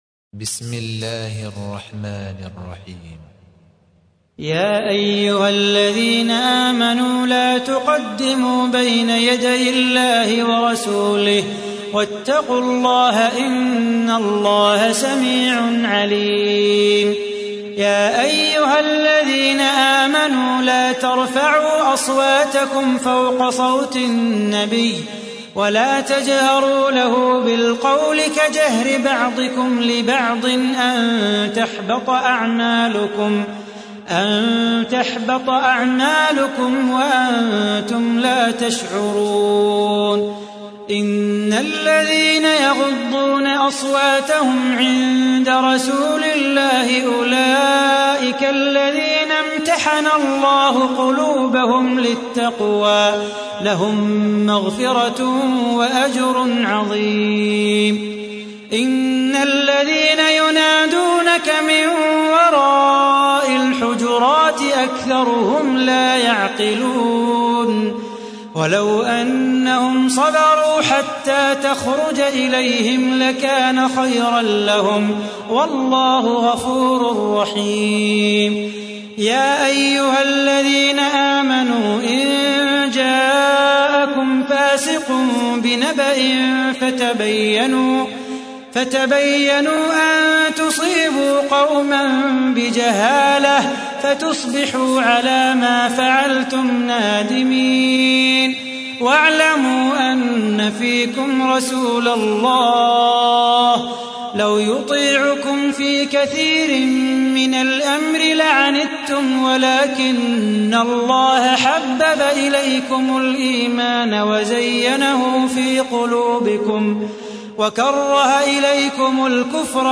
تحميل : 49. سورة الحجرات / القارئ صلاح بو خاطر / القرآن الكريم / موقع يا حسين